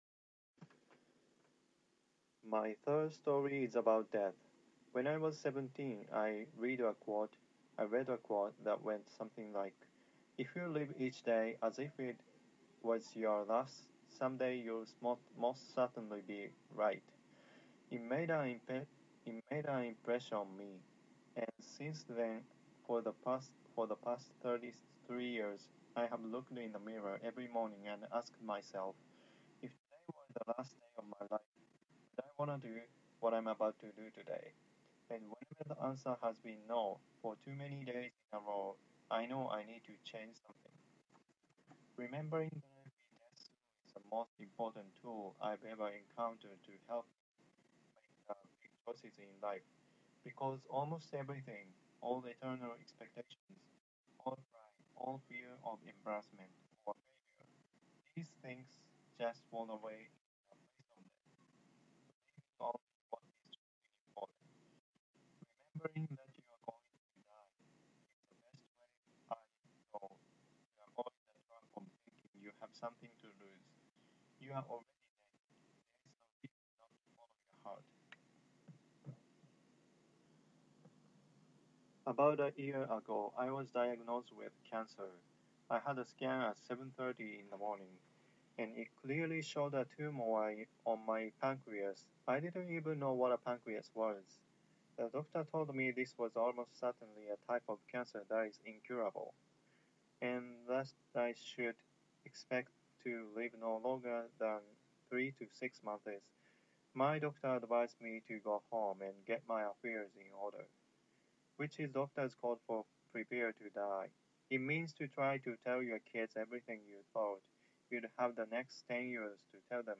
Steve Jobs Speech Part 2